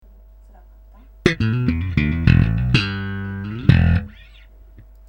ESP J-BASS (Custom Made)
Pickup Saimor Dancan Quater Pound
シェクターが付けてあった時には非常に個性的な音でしたが、ダンカンに換えてから素直で太い音になりました。
音色はオーソドックスなJazz Bass系ですが、高出力で太い音がします。音のヌケがよく、ジャンルを選ばない音色です。
esp-slap.mp3